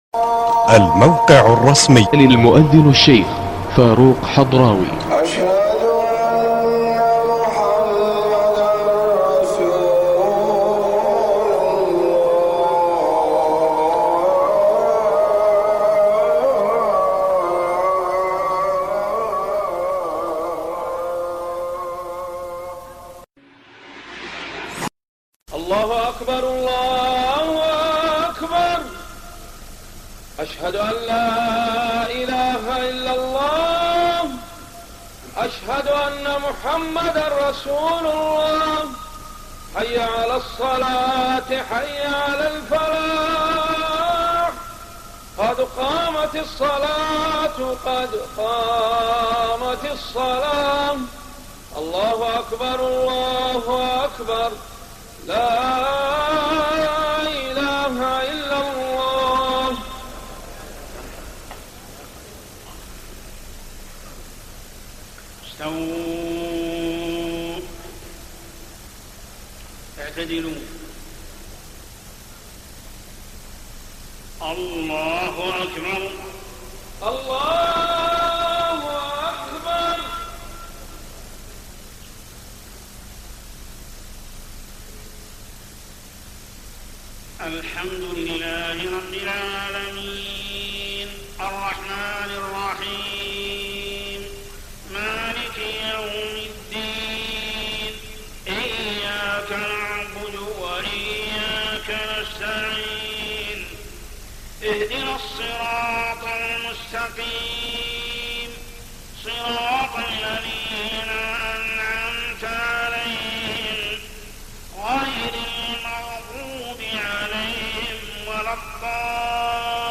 صلاة العشاء 15 رمضان 1423هـ سورتي الشمس و التين > 1423 🕋 > الفروض - تلاوات الحرمين